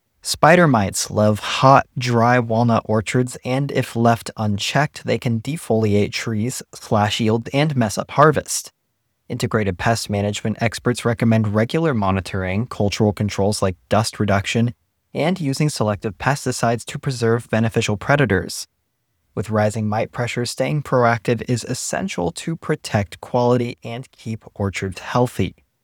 Listen to the audio version of this article. (Generated by A.I.)